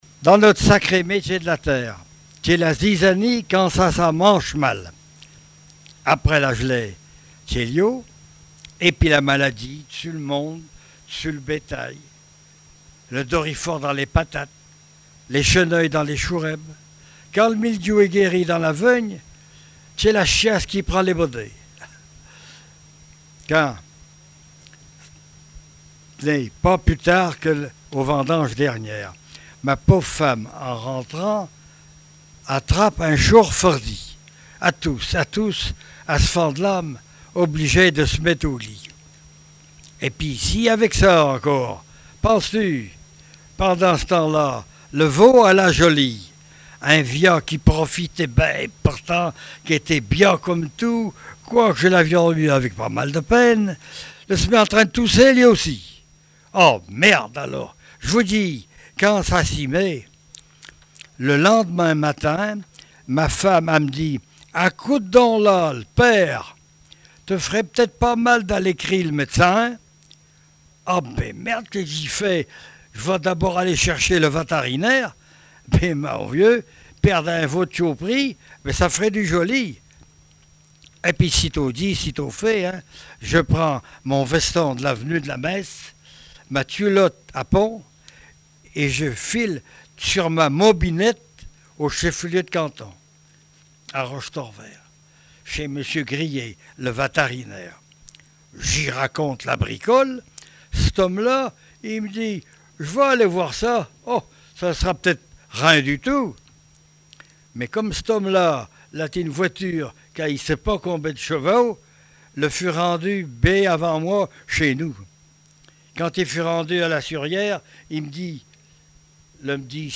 Langue Patois local
Genre sketch
Catégorie Récit